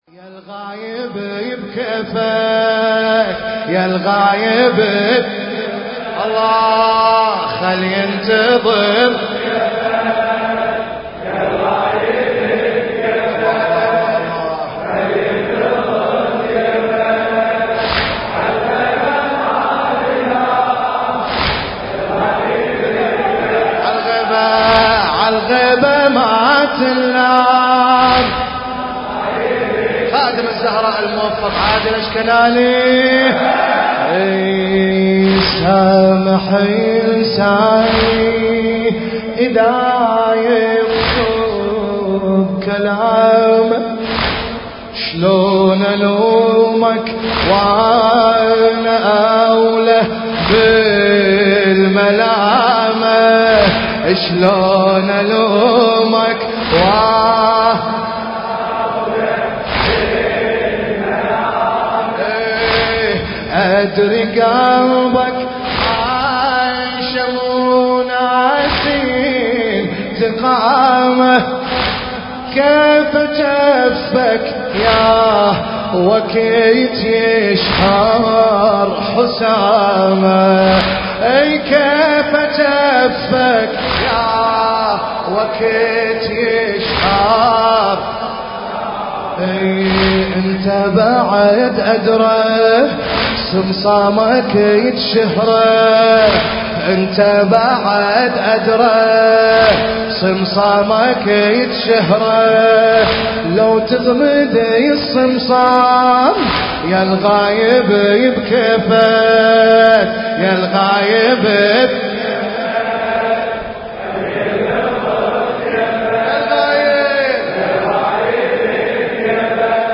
المكان: حسينية المرحوم داوود العاشور/ البصرة